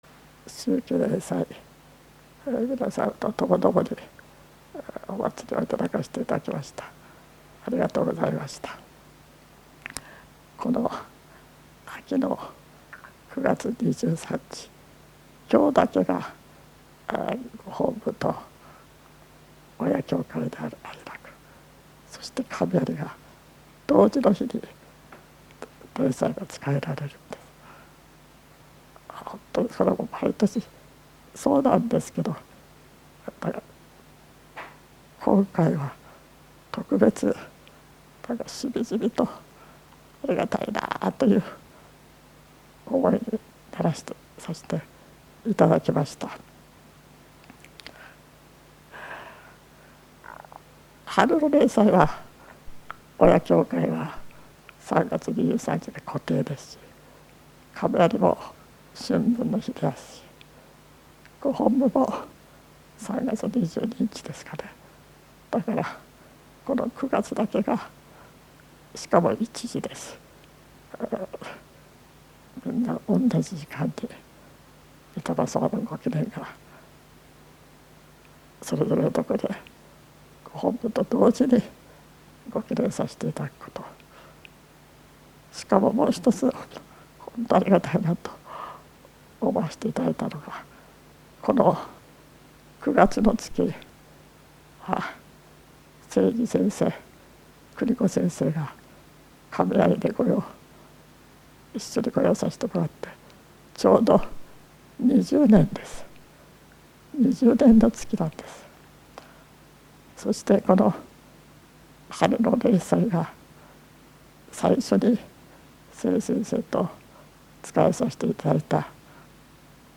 秋季霊祭教話